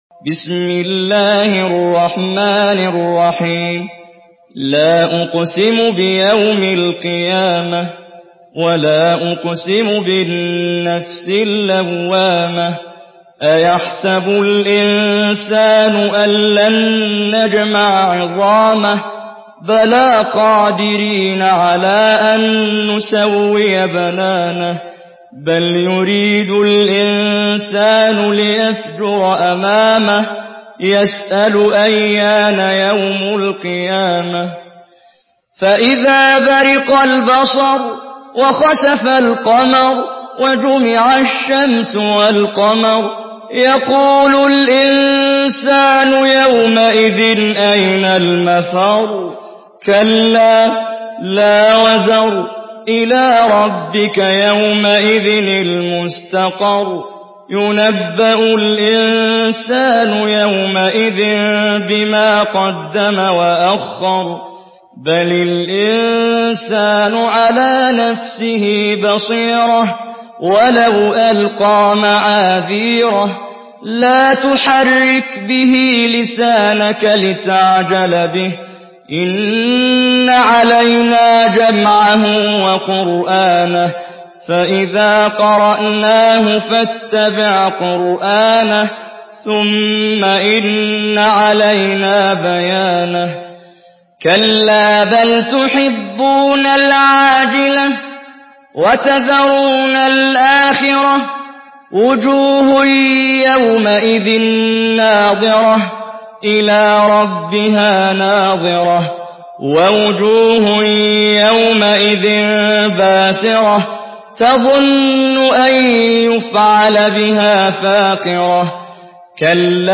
Surah القيامه MP3 by محمد جبريل in حفص عن عاصم narration.
مرتل